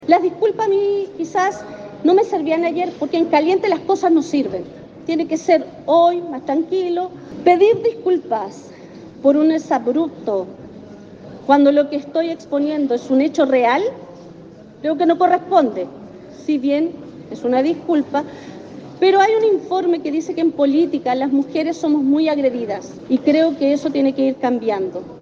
La diputada Delgado, en tanto, aseguró este miércoles que no son suficientes las disculpas que entregó Ávila, sobre todo por la conmemoración durante esta jornada del 8M.